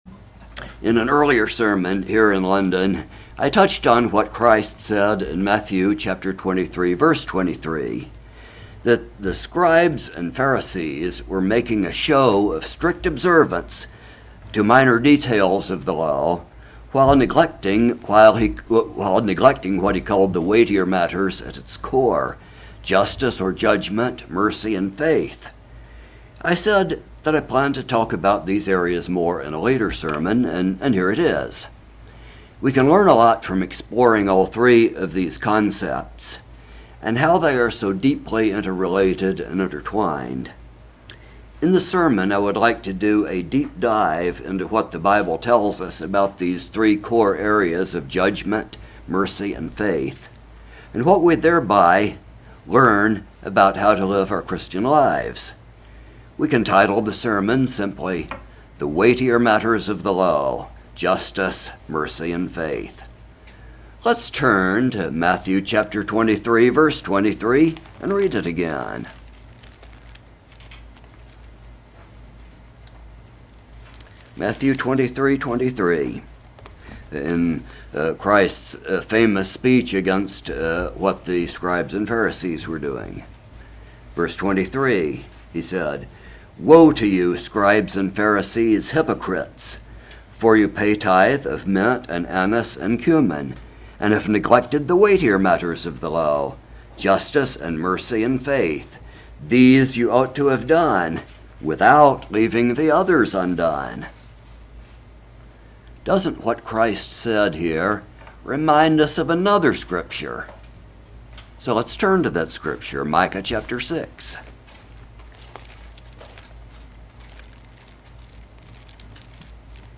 A message examining Jesus Christ’s criticism of the Pharisees that they were deliberately neglecting the weightier matters of the law: Justice, mercy and faith.